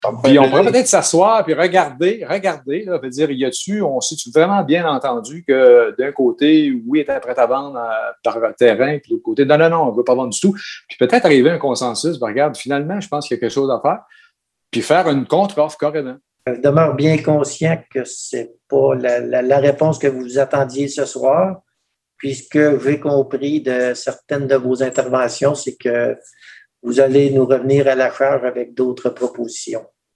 suivi du conseiller Pierre Moras, qui s’engage à être à l’écoute des citoyens :